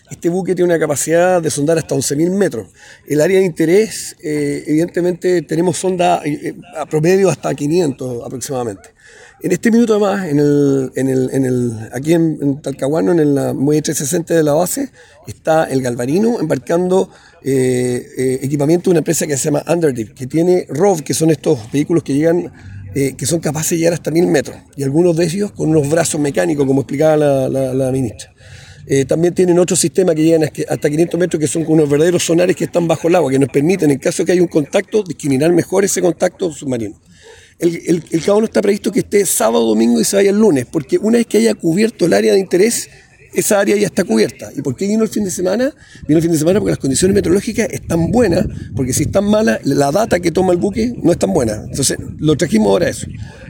Por su parte, el comandante en jefe de la Segunda Zona Naval, contraalmirante Arturo Oxley, detalló las capacidades del Cabo de Hornos, indicando que “cuenta con sonares submarinos que nos permiten, en el caso de que haya una señal, discriminar mejor ese contacto”.